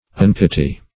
unpity - definition of unpity - synonyms, pronunciation, spelling from Free Dictionary Search Result for " unpity" : The Collaborative International Dictionary of English v.0.48: Unpity \Un*pit"y\, n. Want of piety.